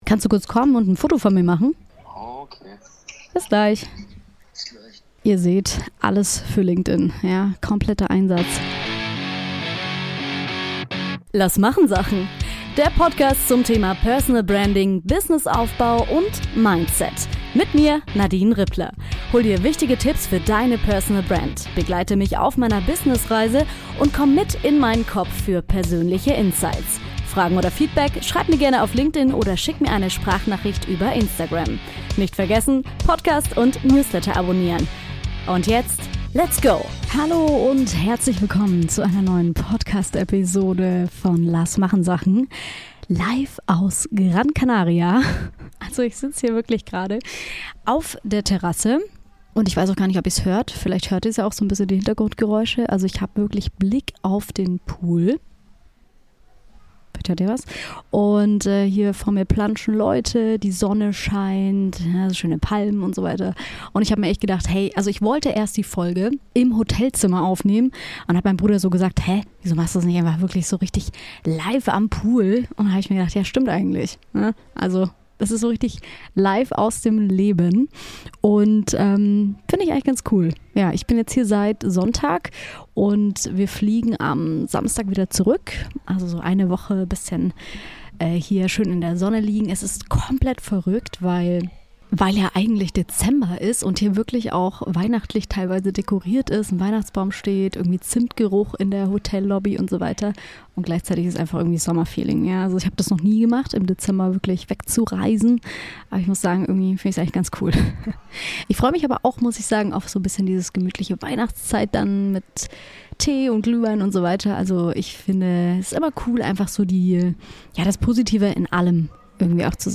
Ich sende diese Woche live aus Gran Canaria! ;) Was mir auf Reisen klar wird, wieso mein Bruder mit dabei ist und was man beachten sollte, wenn man Content liebt und gleichzeitig ein erfolgreiches Business aufbauen möchte.